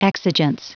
Prononciation du mot exigence en anglais (fichier audio)
Prononciation du mot : exigence